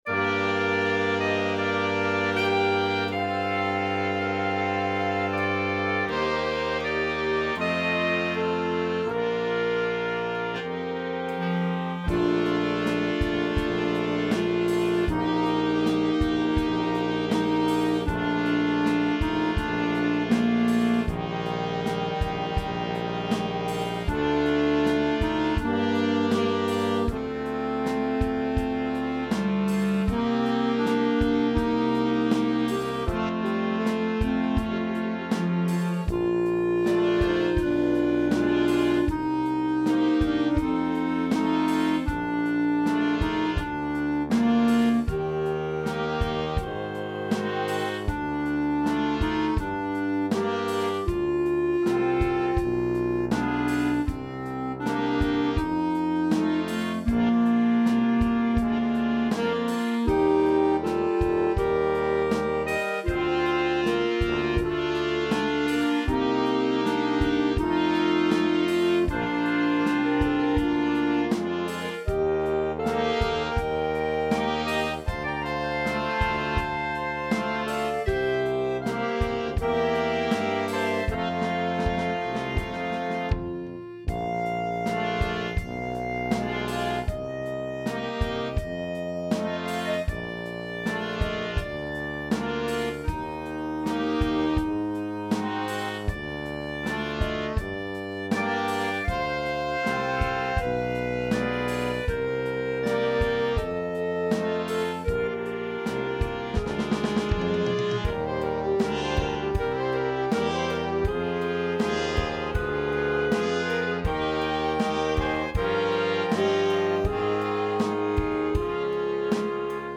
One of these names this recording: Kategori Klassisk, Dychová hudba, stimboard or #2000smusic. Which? Dychová hudba